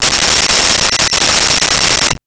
Cell Phone Ring 04
Cell Phone Ring 04.wav